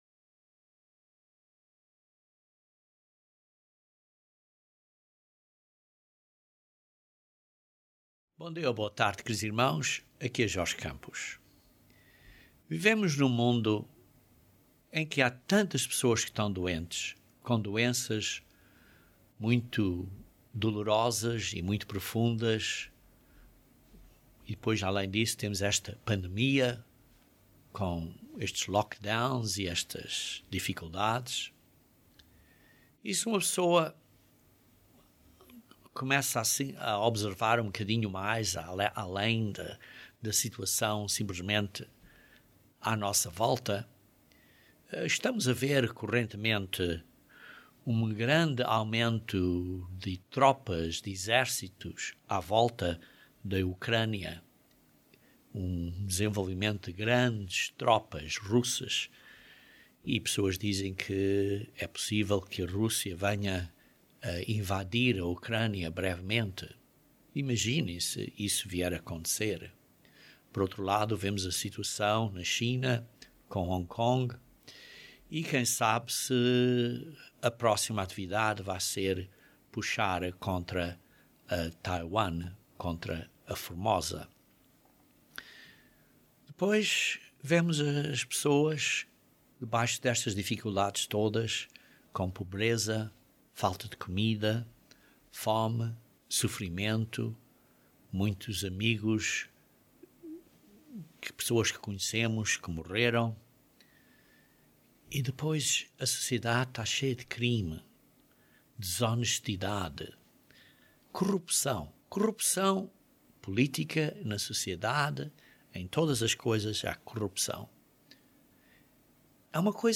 Este sermão descreve como isto é para o bem ao fim de tudo - e o fim de tudo é parte dum plano de sete mil anos. Sim Deus vai intervir brevemente, e quando Ele completar a Sua grande intervenção então veremos como Ele é sábio, misericordioso, cheio de graça e de amor por nós.